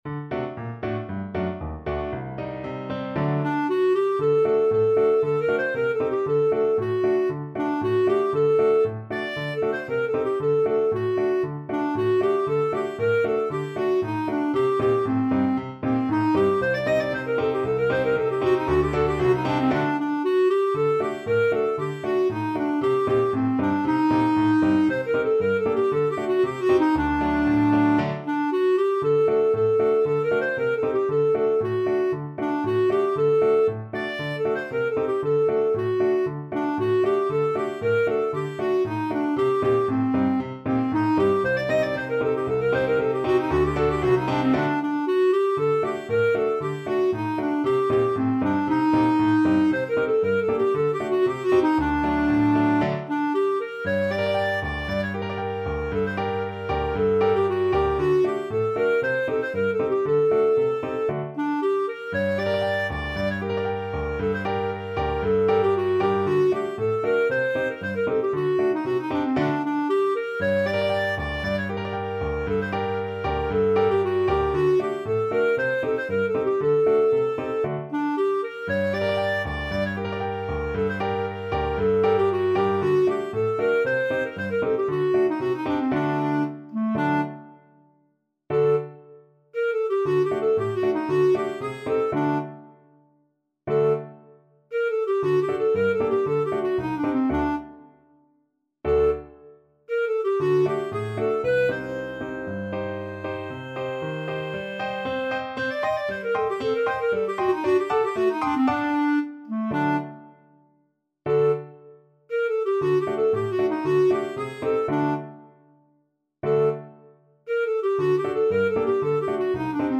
Traditional Trad. Der Heyser Bulgar (Klezmer) Clarinet version
Clarinet
G minor (Sounding Pitch) A minor (Clarinet in Bb) (View more G minor Music for Clarinet )
2/4 (View more 2/4 Music)
Allegro =c.116 (View more music marked Allegro)
Traditional (View more Traditional Clarinet Music)